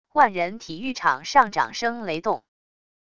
万人体育场上掌声雷动wav音频